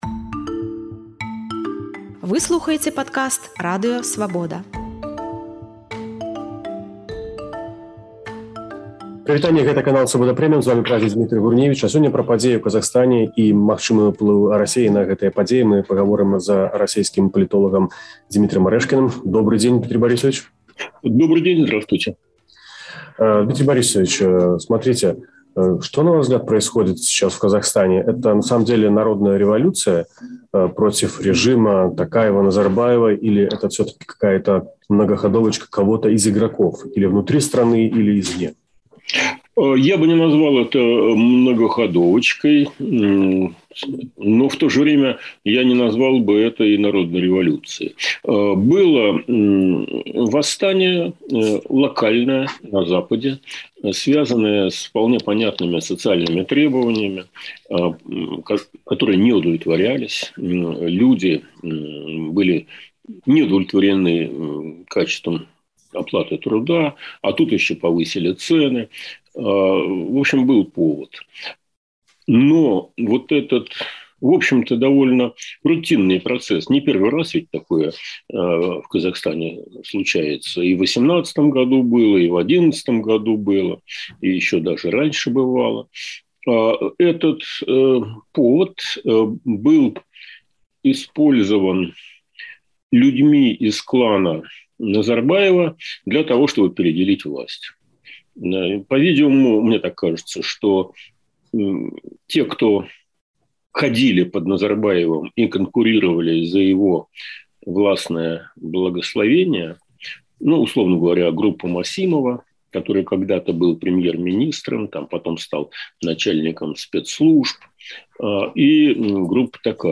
Маскоўскі палітоляг Дзьмітрый Арэшкін у інтэрвію «Свабодзе» прадстаўляе сваю вэрсію падзей у Казахстане, расказвае пра расейскія інтарэсы ў гэтай краіне і тлумачыць, чаму Маскву не задавальняў Нурсултан Назарбаеў.